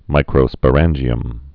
(mīkrō-spə-rănjē-əm)